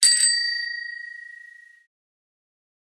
bicycle-bell_08
bell bells bicycle bike bright chime chimes clang sound effect free sound royalty free Memes